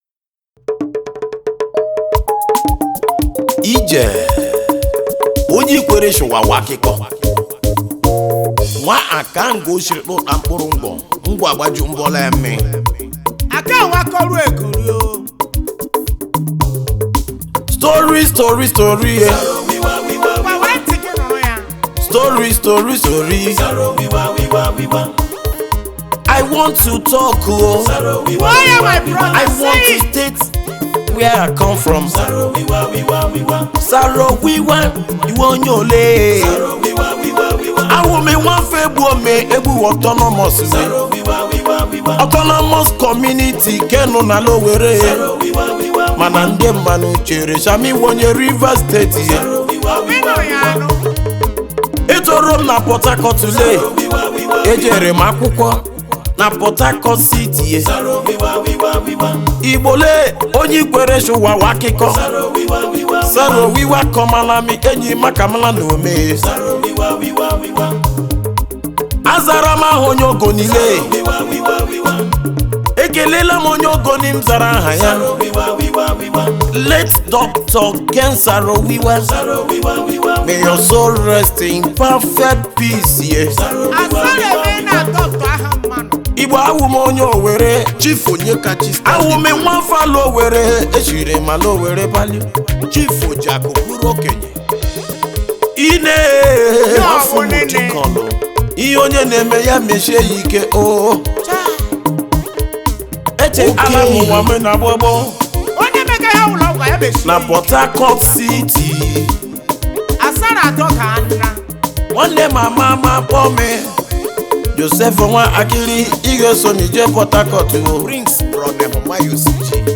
well versed highlife track